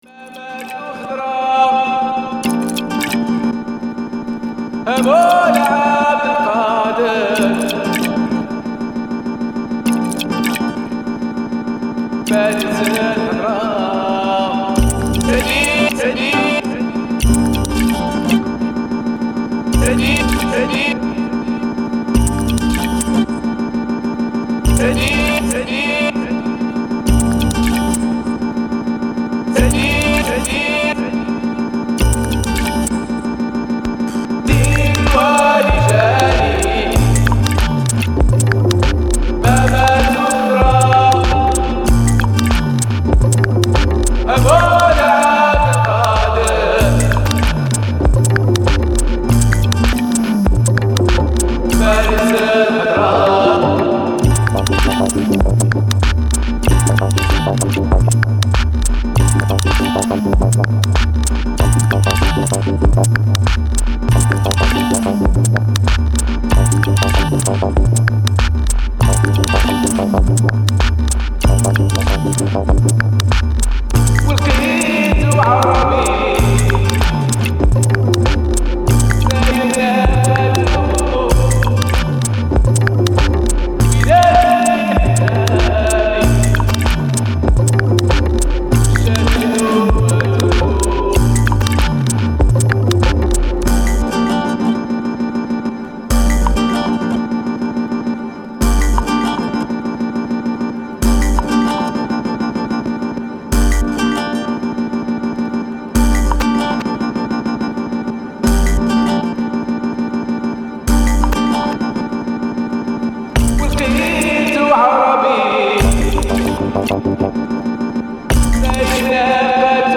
Arab Disco Dub Remix